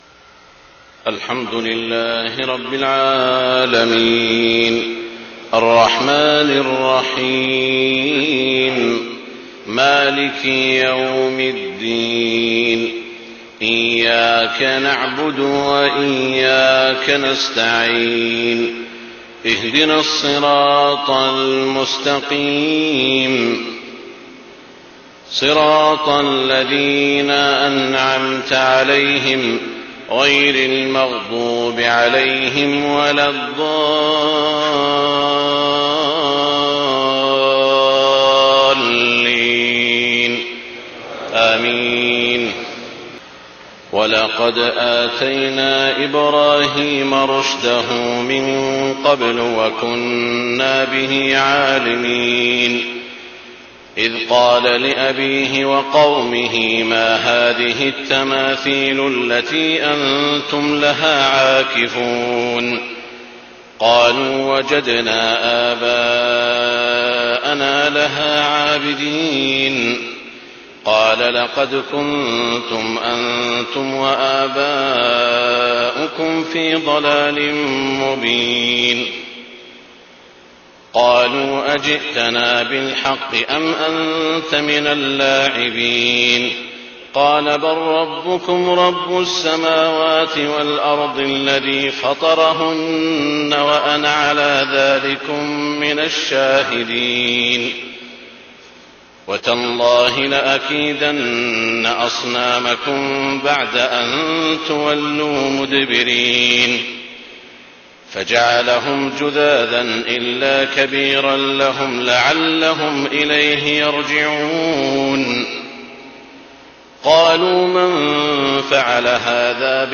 صلاة الفجر 12 شعبان 1430هـ من سورة الأنبياء 51-80 > 1430 🕋 > الفروض - تلاوات الحرمين